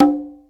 washboard_c.ogg